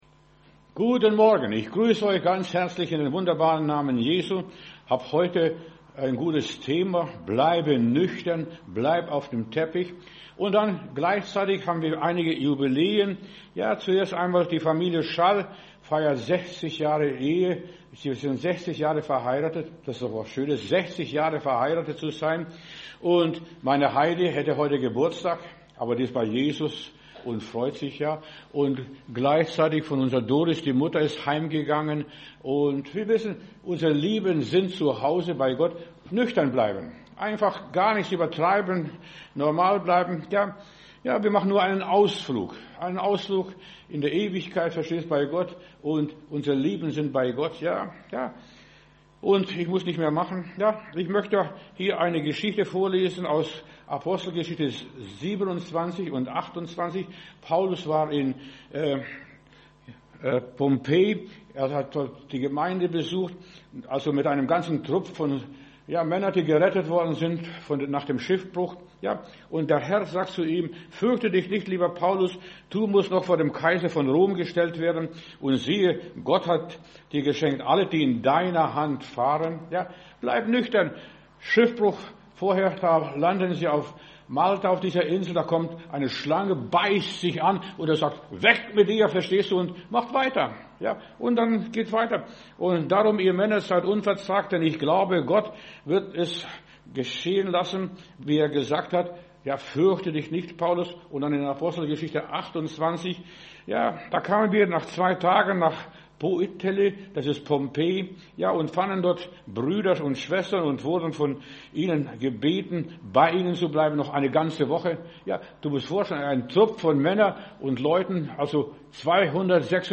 Predigt herunterladen: Audio 2026-02-01 Bleibe nüchtern Video Bleibe nüchtern